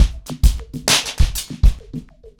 PrintOuts-100BPM.7.wav